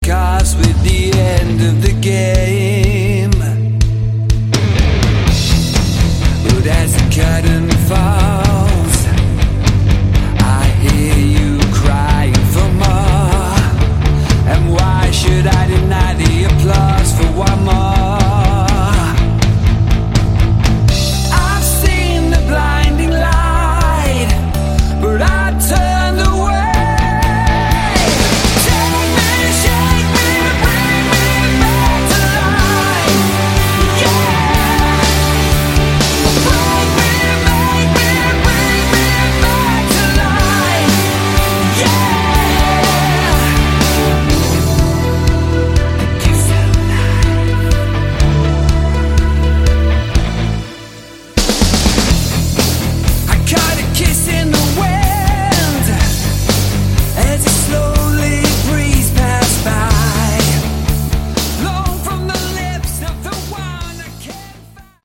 Category: Melodic Rock